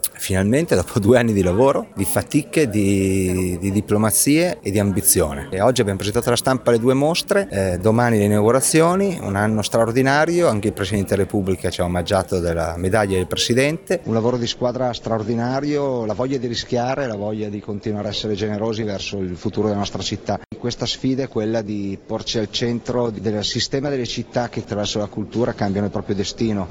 Entusiasmo e soddisfazione sono stati espressi anche dal sindaco di Mantova, Mattia Palazzi, che ha dichiarato doveroso questo omaggio a Giulio Romano, ecco le sue parole: